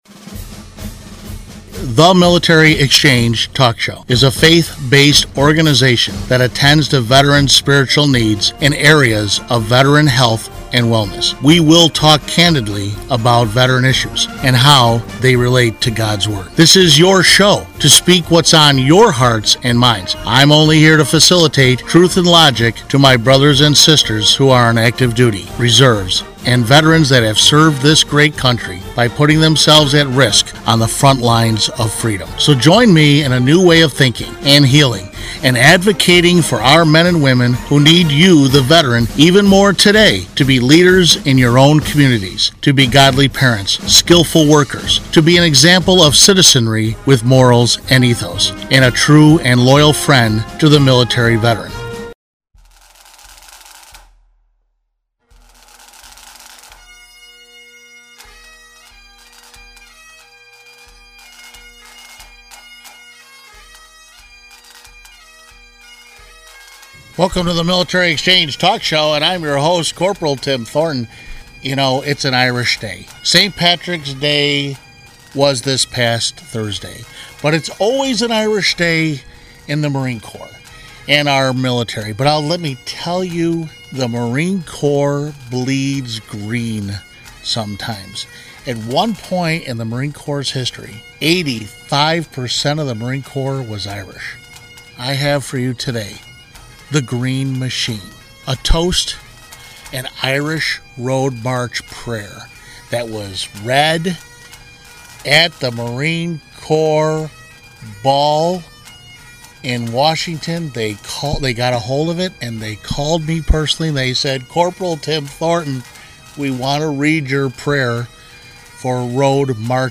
The Military Exchange Talk Show